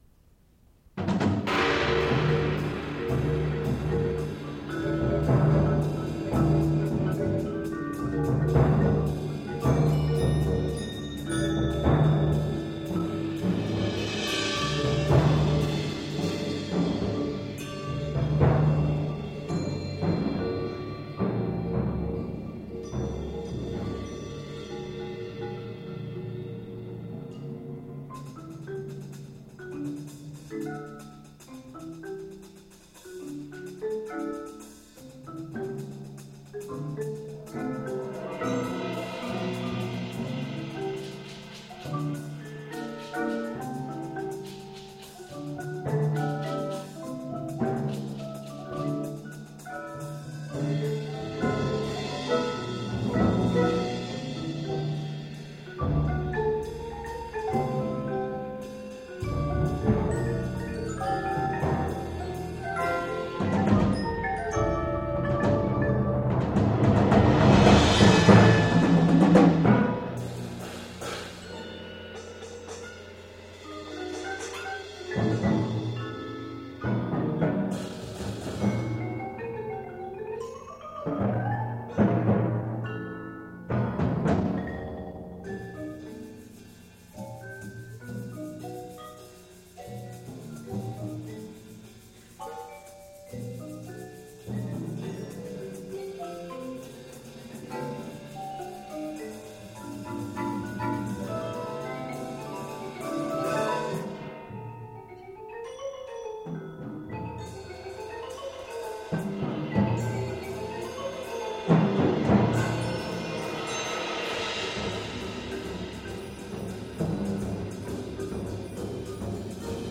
Genre: Percussion Ensemble
# of Players: 9
Player 7 (Timpani)